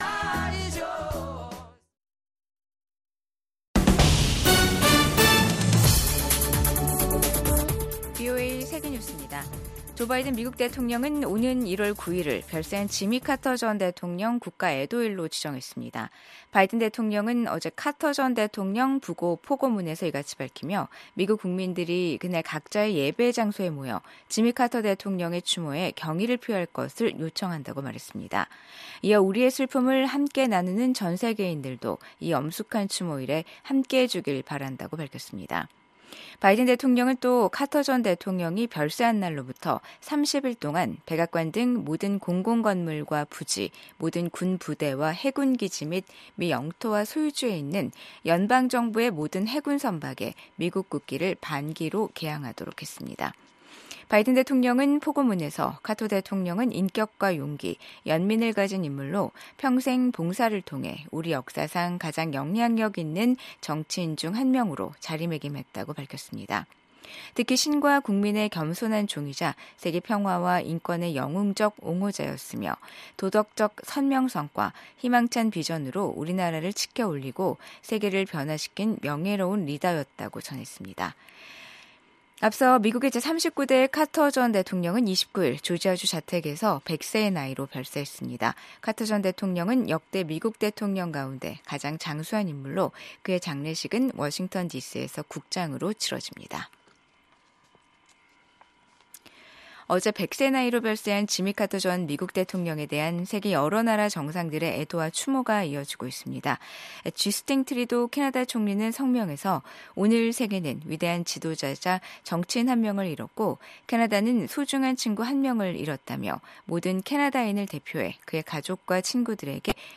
VOA 한국어 방송의 간판 뉴스 프로그램입니다. 한반도와 함께 미국을 비롯한 세계 곳곳의 소식을 빠르고 정확하게 전해드립니다. 다양한 인터뷰와 현지보도, 심층취재로 풍부한 정보를 담았습니다.